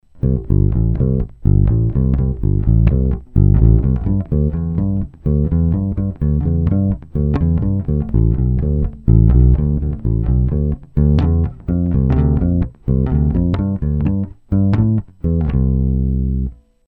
Mizerný nahrávky spíchnutý za pár minut.. Tempo nic moc, přeznívání atd. Není to pořádný A/B porovnání - ani dvě z nich nemaj stejný struny, 3*flat, 1*round..
olše/brazilskej palisandr/krkovej snímač/bez tónovky